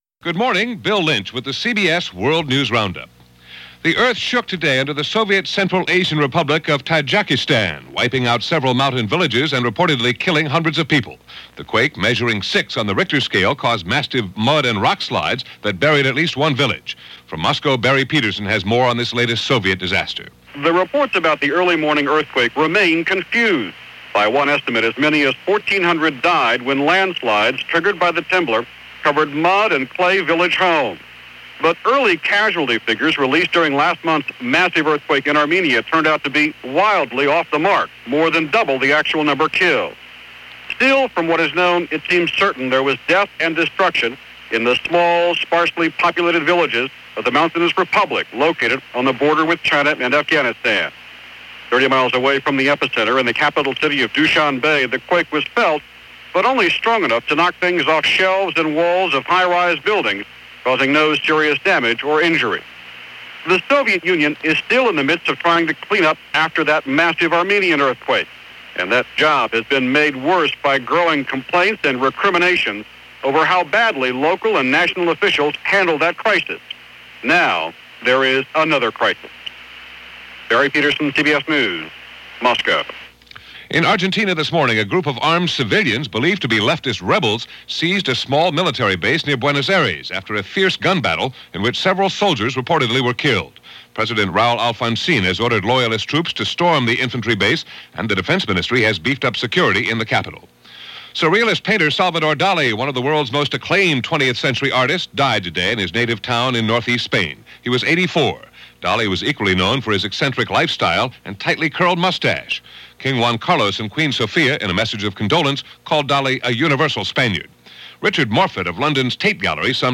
January 23, 1989 – CBS World News Roundup – Gordon Skene Sound Collection –
All that, and so much more for this January 23, 1989 as reported by The CBS World News Roundup.